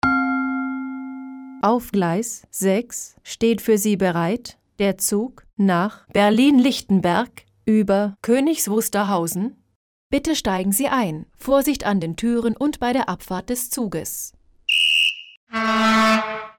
Lautsprecheransagen (Abfahrten)
• Gong (einfach)
• Abfahrtspfiff der Aufsicht mit Quittungston vom Signalhorn einer Diesellok